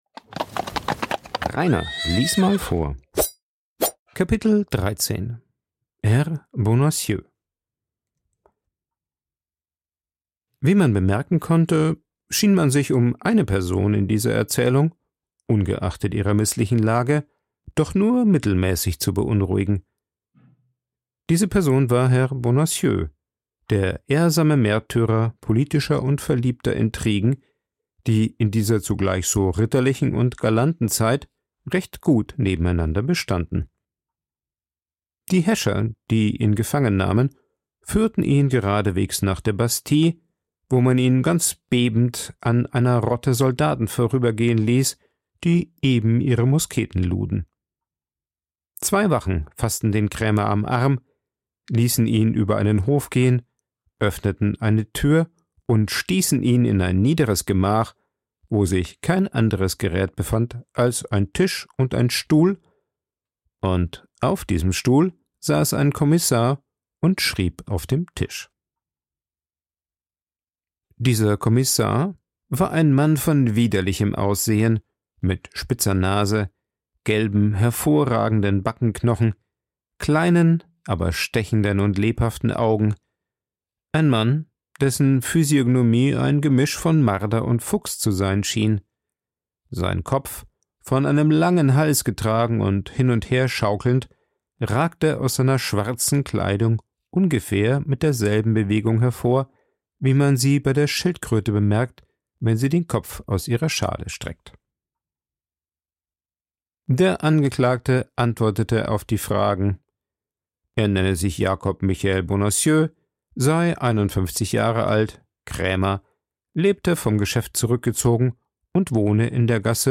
Als Alles nichts hilft wird Bonacieux am nächsten Tag per Gefängniskutsche abtransportiert. Vorgelesen
aufgenommen und bearbeitet im Coworking Space Rayaworx, Santanyí, Mallorca.